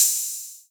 HHo808.wav